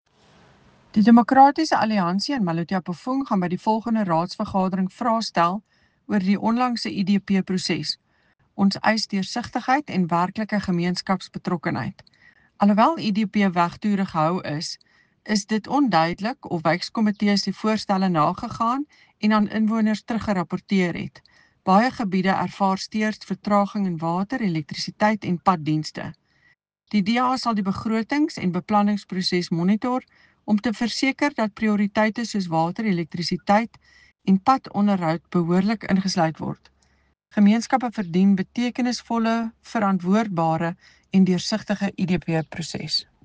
Afrikaans soundbite by Cllr Eleanor Quinta.